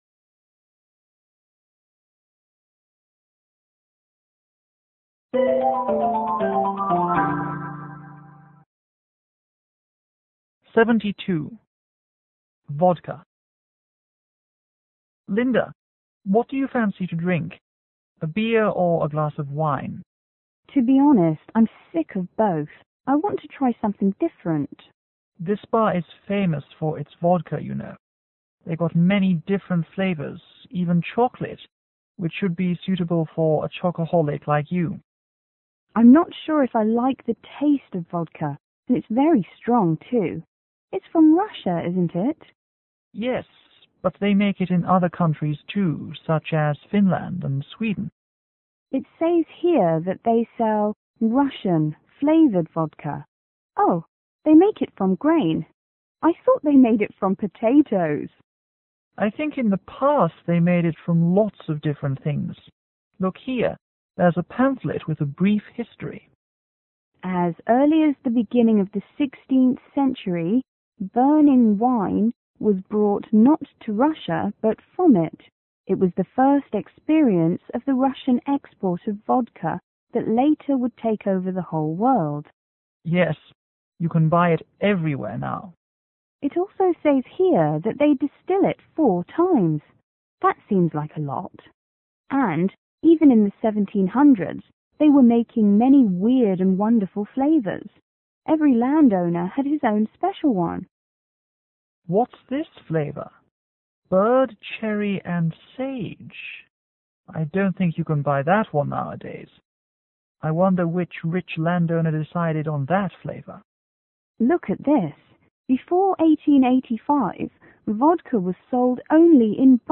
M:Man      W:Woman